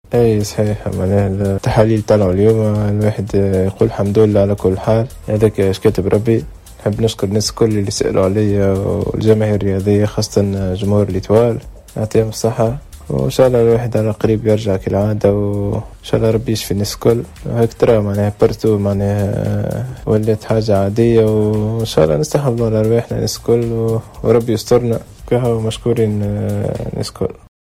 تحدث اللاعب الدولي للنجم الساحلي محمد امين بن عمر في تصريح خاص و حصري بجوهرة افم عن اصابته بفيروس كوفيد-19 بعد صدور كافة التحاليل الخاصة بفيروس كورونا و التي كان قد اجراها كامل الفريق من لاعبين و اطار اداري و فني و طبي البارحة مباشرة بعد ثبوت اصابة قائد الفريق ياسين الشيخاوي.